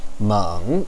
meng3.wav